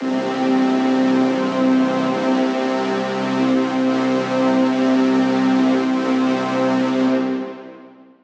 DDW Pad.wav